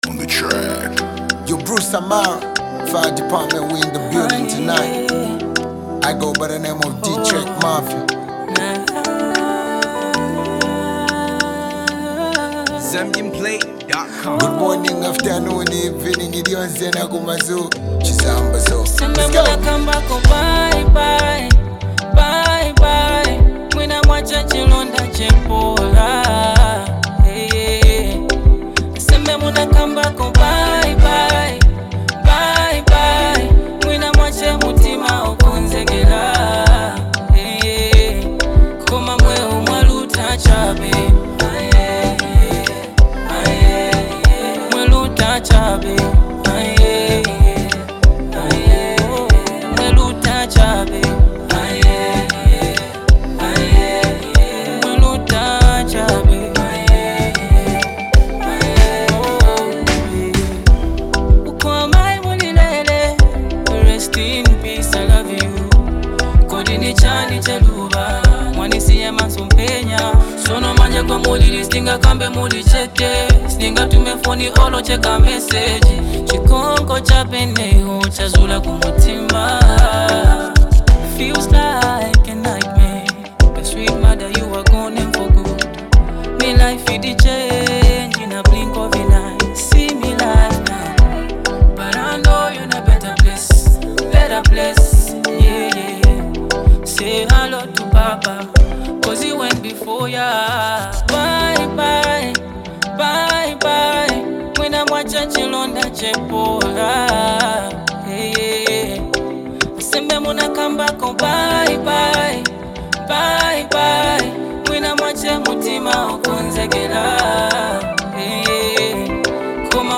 Zambian gospel music
a profoundly soulful and emotional single